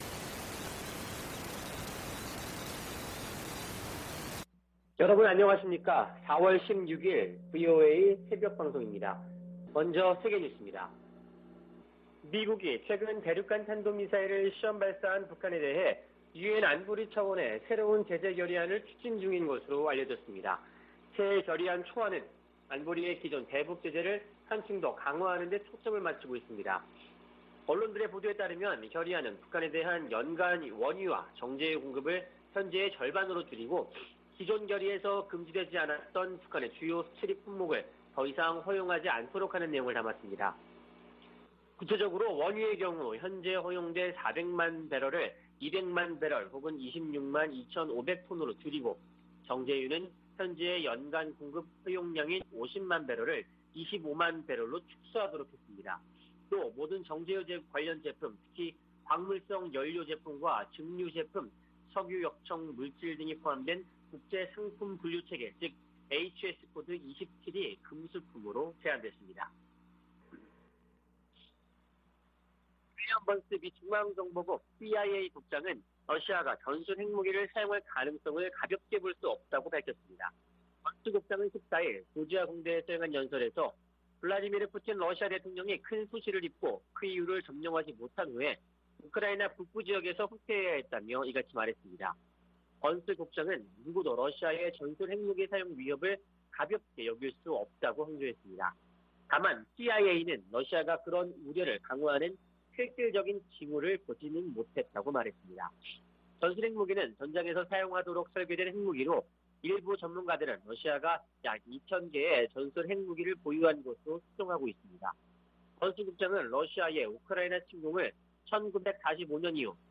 VOA 한국어 '출발 뉴스 쇼', 2022년 4월 16일 방송입니다. 미국의 새 대북 제재 결의안에서 원유와 정제유 공급 상한선을 절반으로 줄이는 등의 내용이 확인됐습니다. 러시아는 한반도 정세를 악화시키는 어떤 조치에도 반대한다며 미국이 추진 중인 새 안보리 대북 결의에 반대 입장을 밝혔습니다. 북한은 아직 미국을 타격할 수 있는 핵탄두 탑재 ICBM 역량을 보유하지 못했다고 백악관 국가안보보좌관이 밝혔습니다.